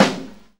AMB JAZZ SNR.wav